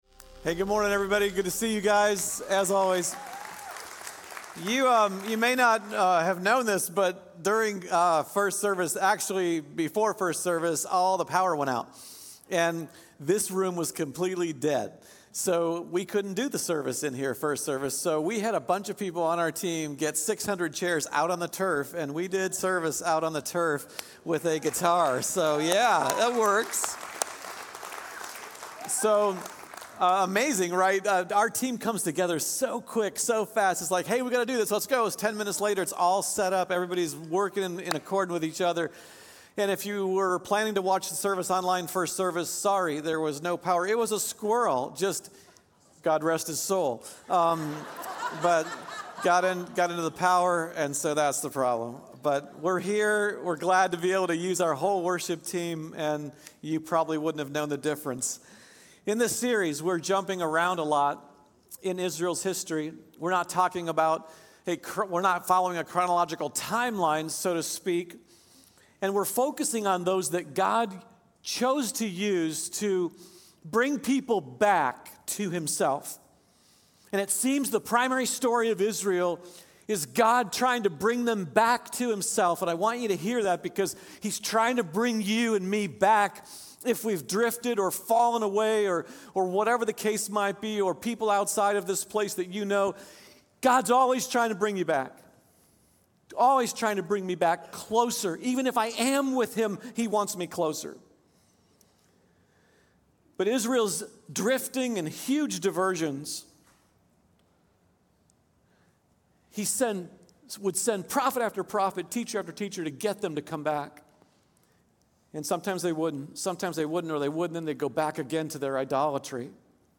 Building Character #9 | Josiah, sermon